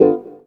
137 GTR 3 -L.wav